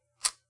玩家互动 " 打火机火石
描述：打火机